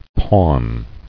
[pawn]